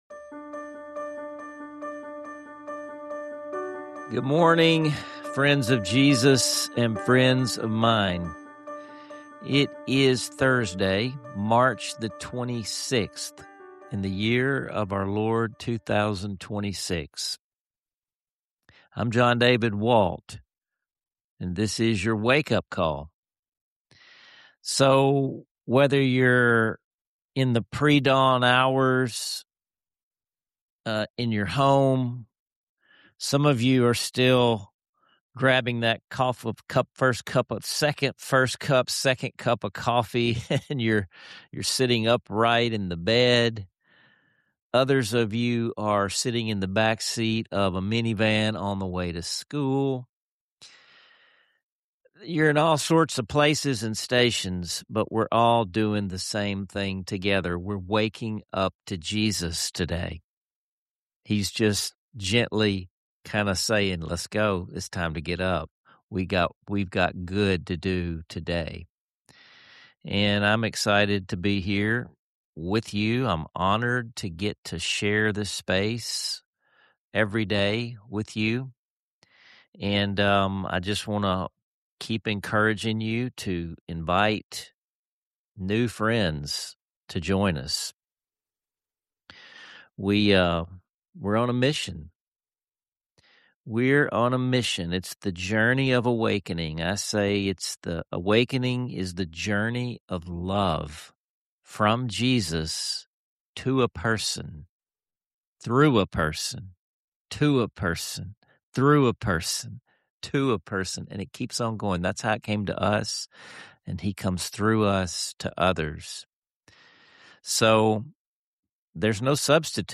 The episode explores the "measuring stick" of transformational growth, offering practical ways to internalize agape love, and asks the big question: Are you growing? Along the way, you'll hear personal reflections, journal prompts, and moving medleys of classic hymns, all woven together with warmth and encouragement.